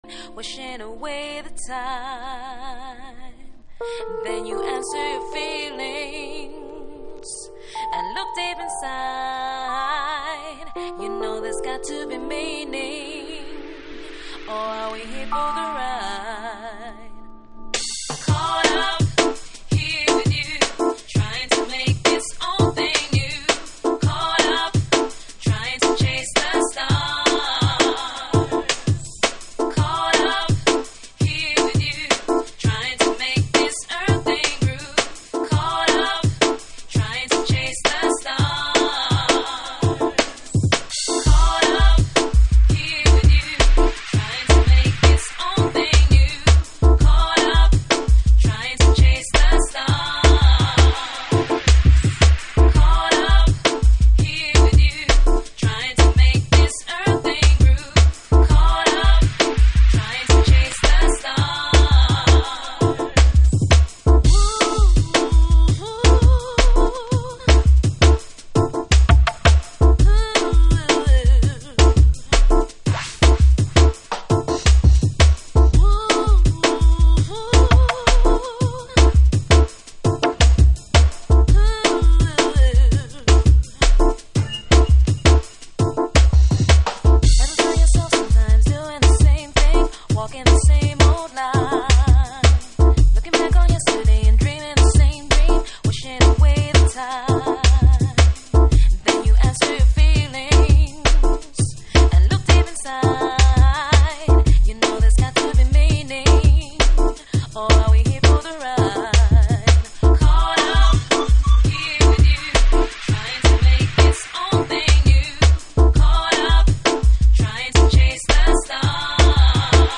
複雑に刻まれサンプリングされたビートとアコースティック群が織りなす新機軸のグルーヴ。